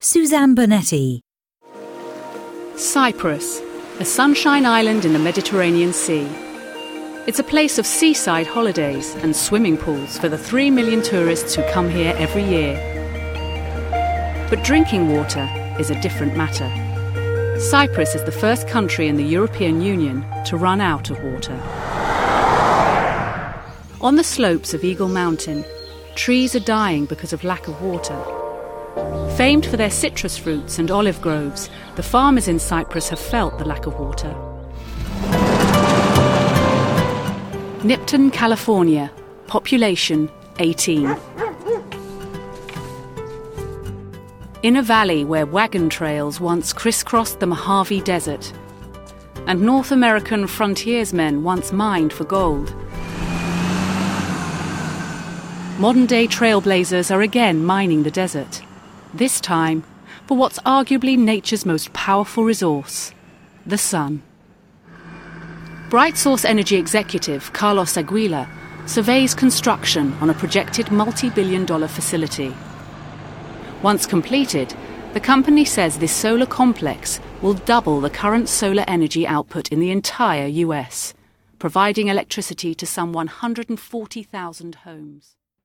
Description: Neutral: gentle, natural, assured
Commercial 0:00 / 0:00
RP*